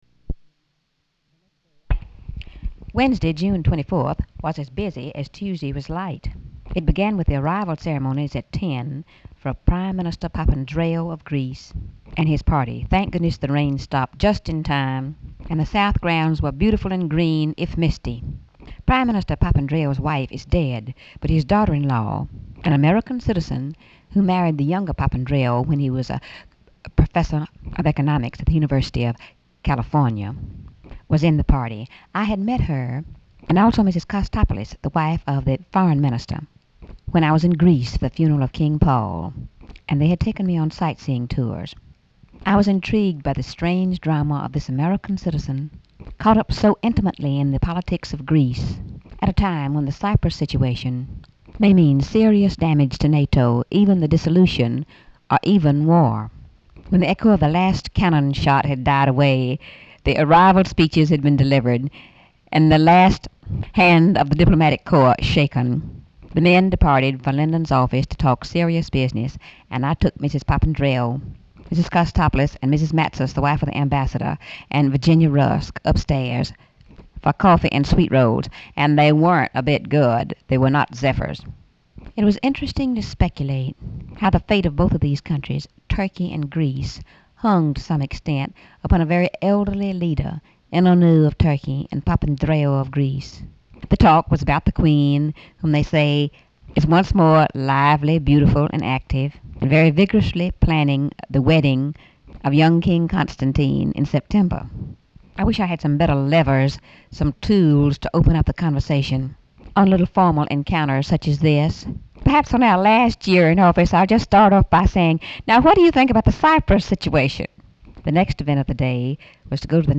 Audio diary and annotated transcript, Lady Bird Johnson, 6/24/1964 (Wednesday) | Discover LBJ